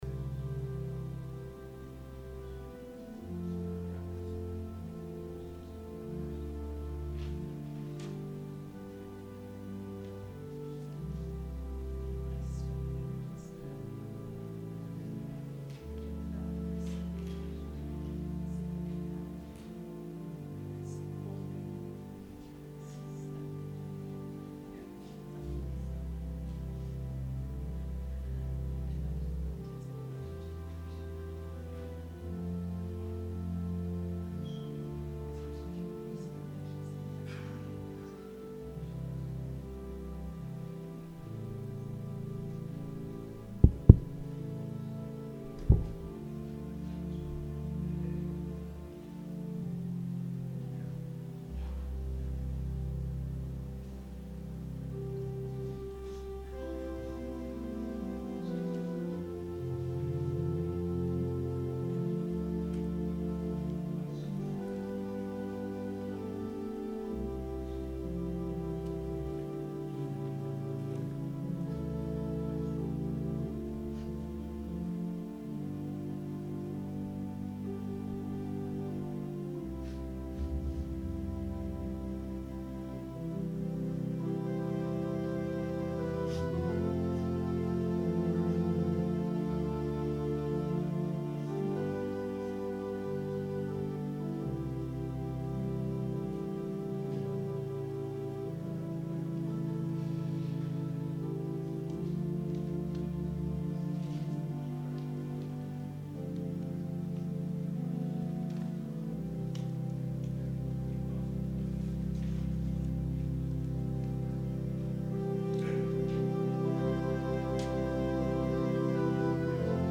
Sermon – December 30, 2018